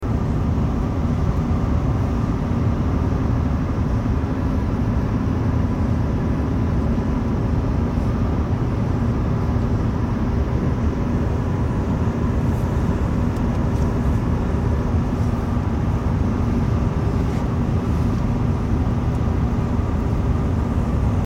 CN Train In Quebec! Sound Effects Free Download